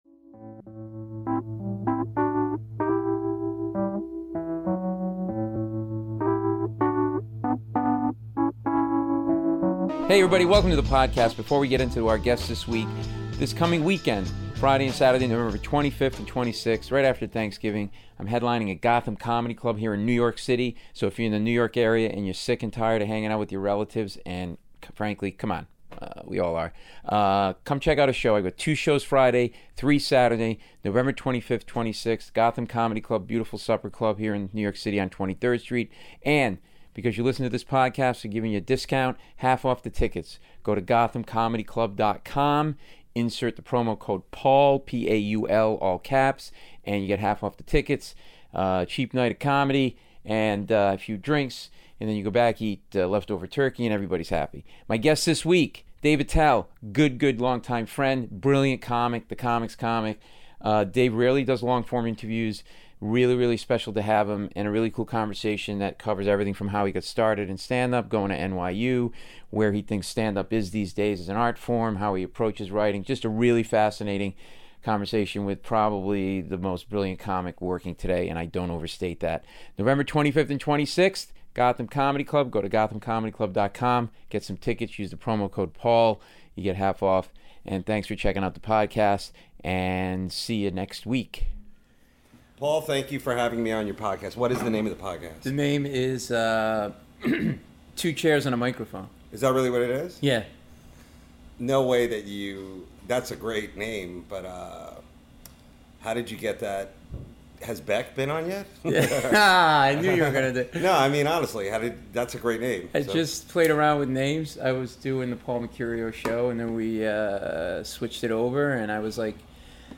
Dave Attell (Paul Mecurio interviews Dave Attell; 21 Nov 2016) | Padverb
We talk how Dave got started, growing up in Long Island, going to NYU, how he approaches his material and the state of stand up comedy right now. Dave doesn't do a lot of long-form interviews so this is a really special episode.